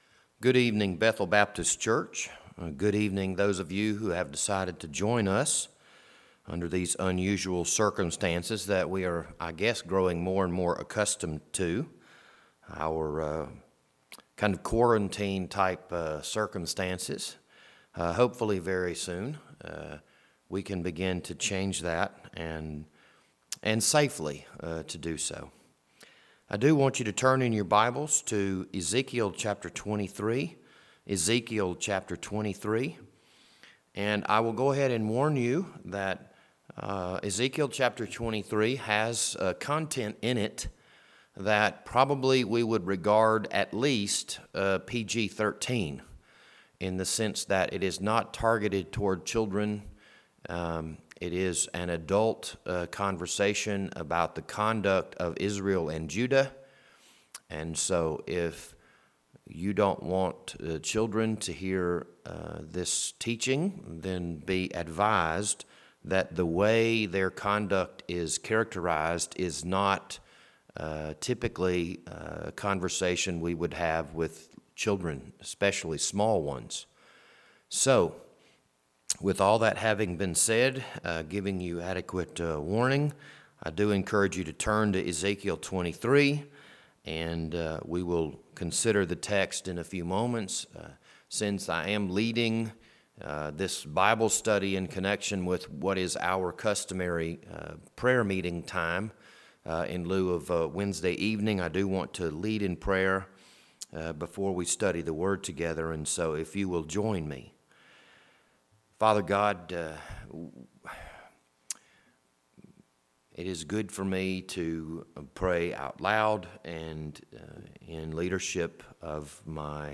This Wednesday evening Bible study was recorded on April 29th, 2020.